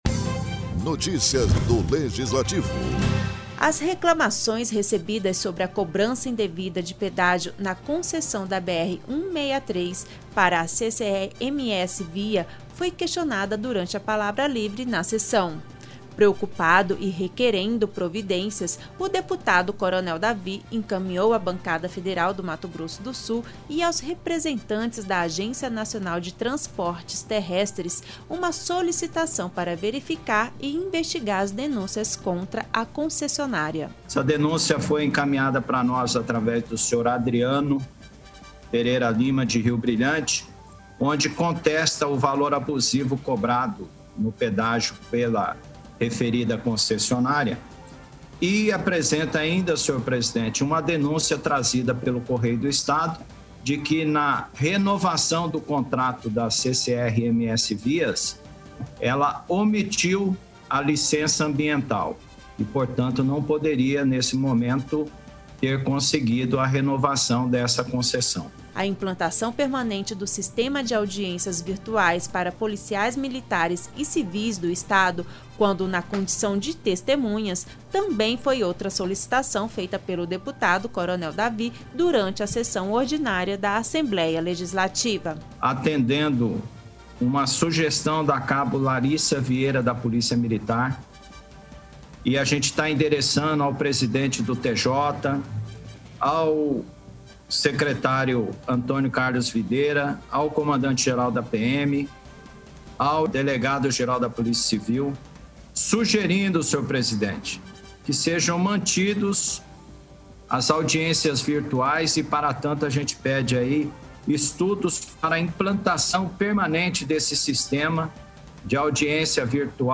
Na sessão remota da Assembleia Legislativa desta quarta-feira (23), o deputado Coronel David apresentou uma indicação solicitando providências na cobrança nos serviços de pedágio na concessão da BR 163 para a CCR MSVia.